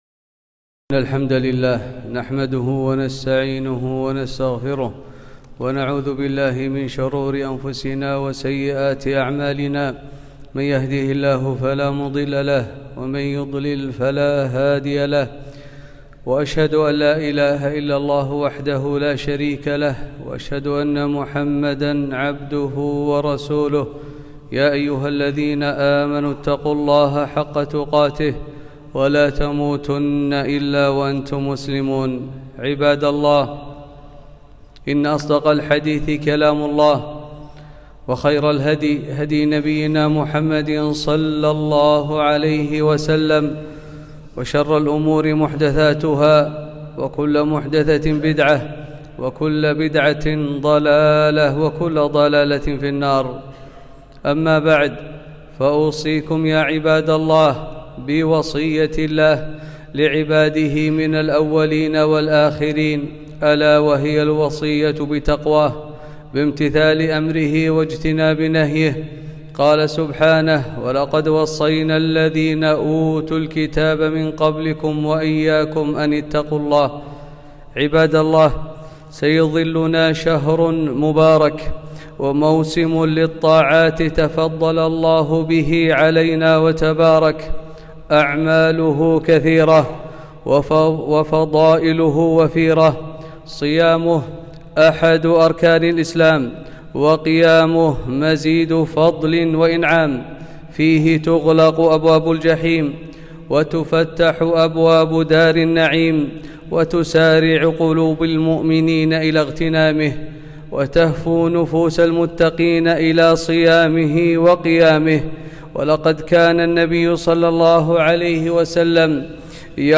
خطبة - رمضان شهر القرآن